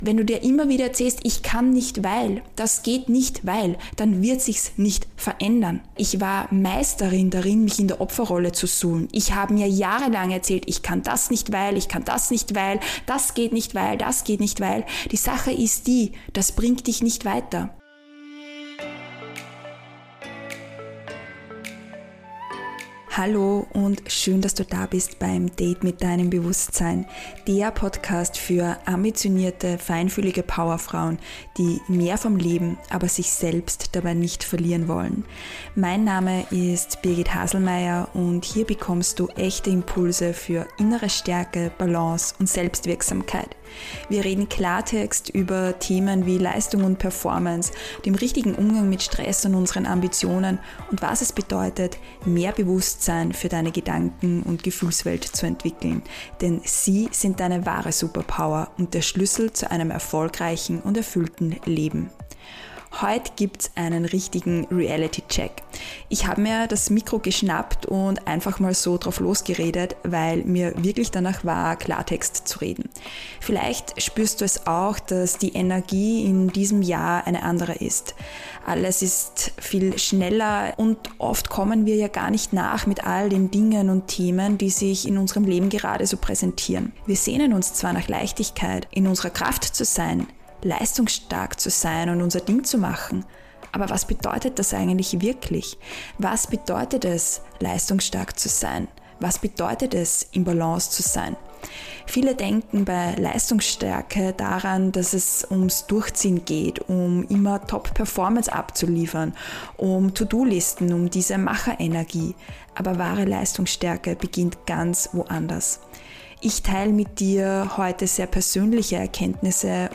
Heute wartet ein liebevoll, direkter Real Talk auf dich.